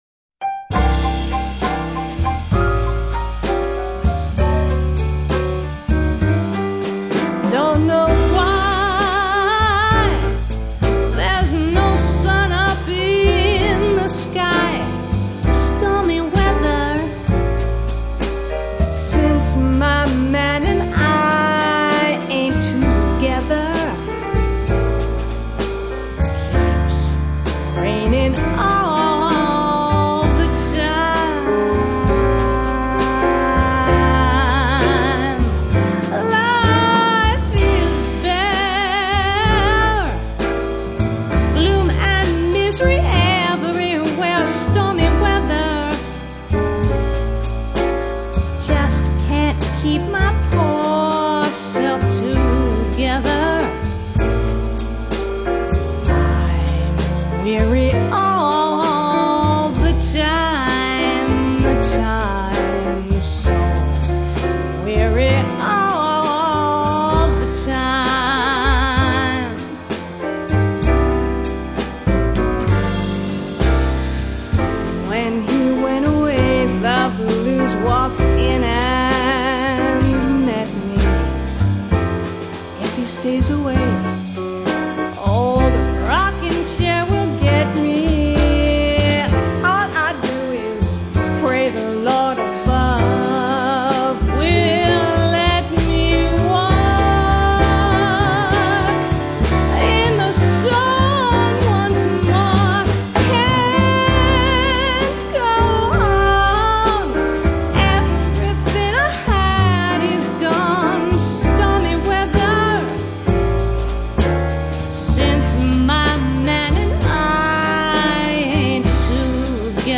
Vocals
Piano
Double Bass
Drums